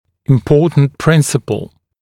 [ɪm’pɔːtnt ‘prɪnsəpl][им’по:тнт ‘принсэпл]важный принцип